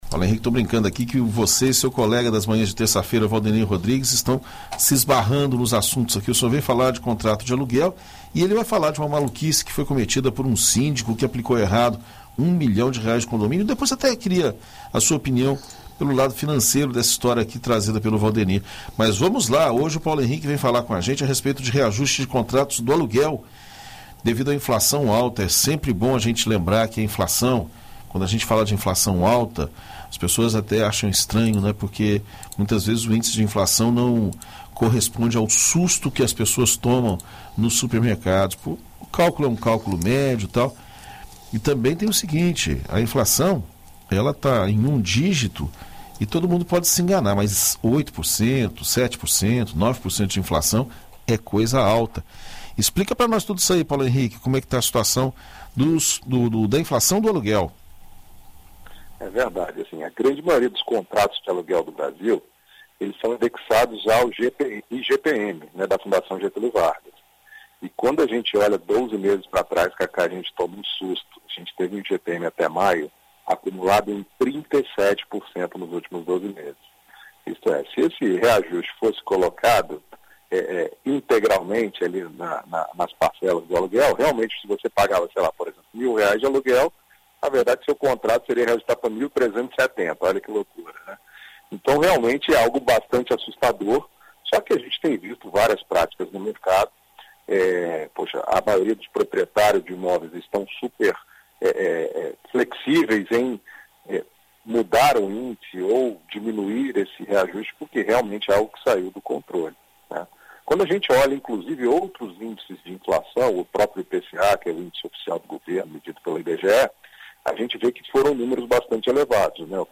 O economista e especialista em mercado financeiro